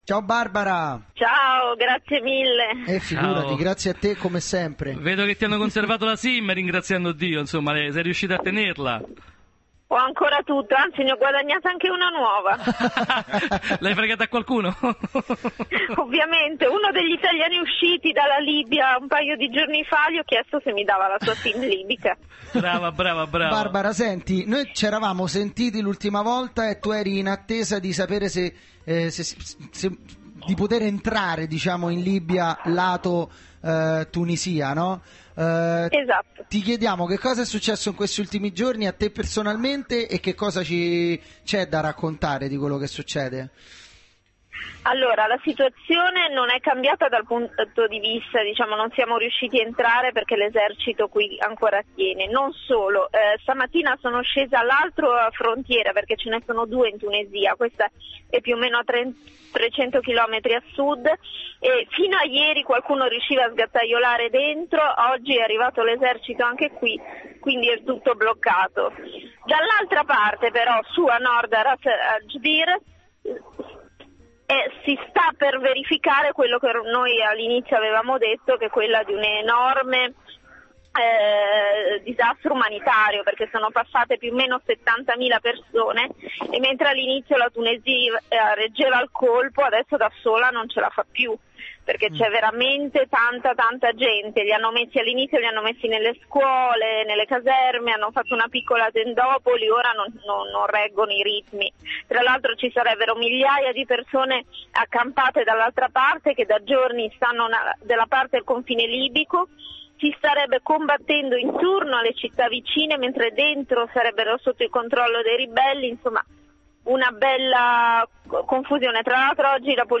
Intervento telefonico